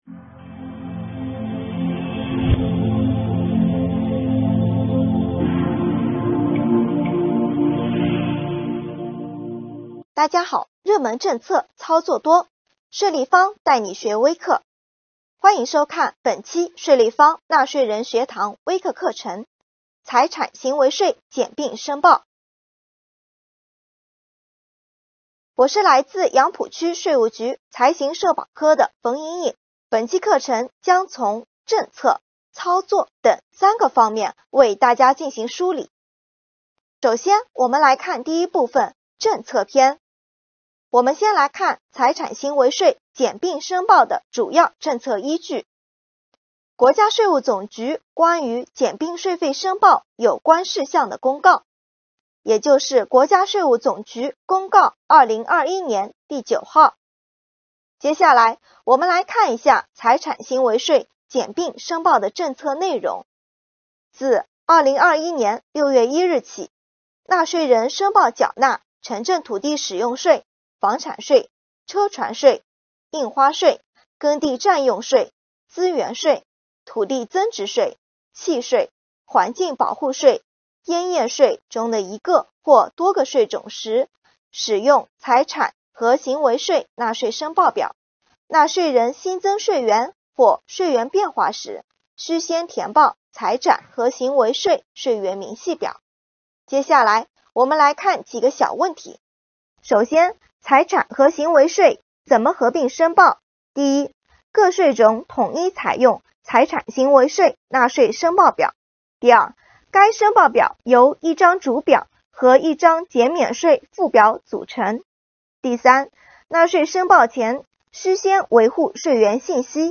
这里有专业讲师为您授课，